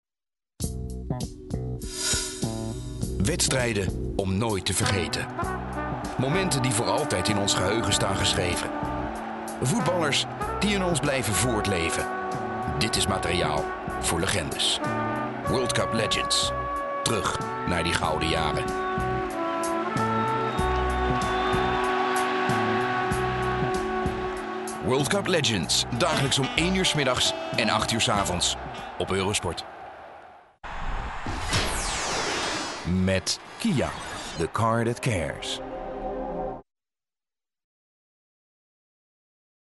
Native Dutch speaker, voice over Eurosport, warm strong voice
Sprechprobe: Sonstiges (Muttersprache):